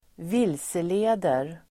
Uttal: [²v'il:sele:der]
vilseleder.mp3